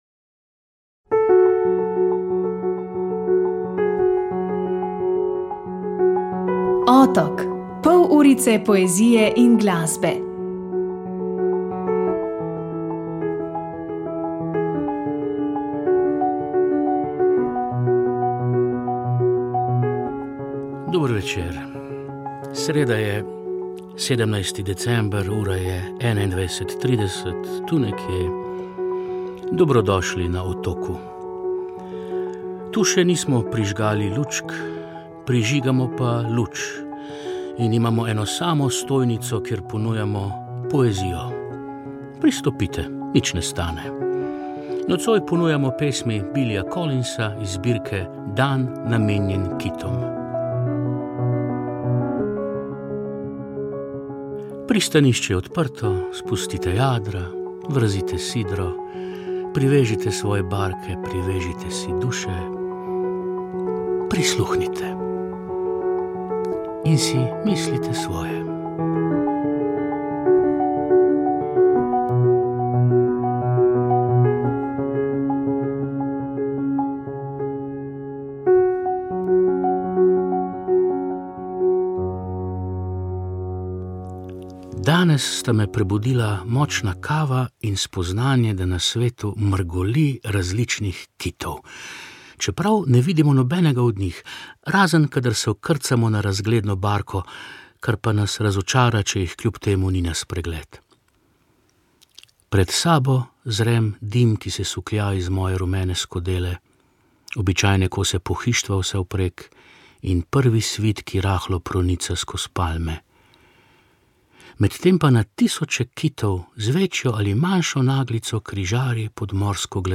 Zgodbe za otroke